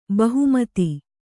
♪ bahu mati